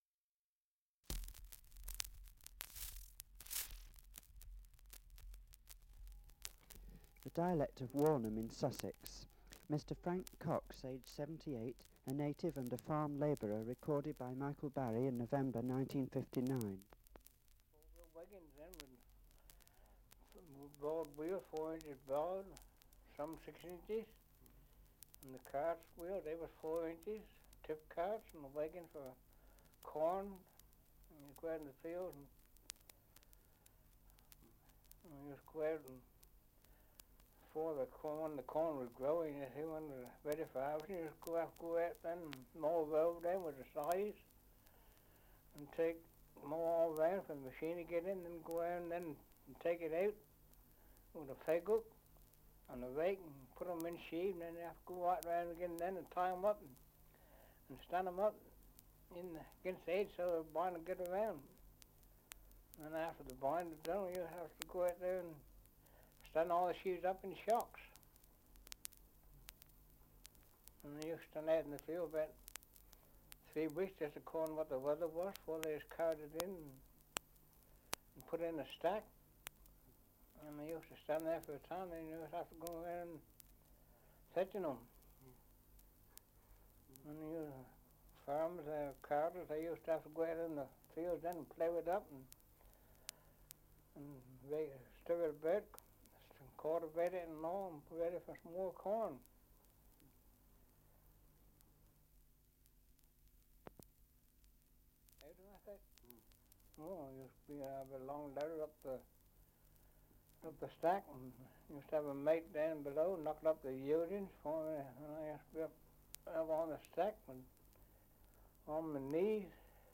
Survey of English Dialects recording in Warnham, Sussex
78 r.p.m., cellulose nitrate on aluminium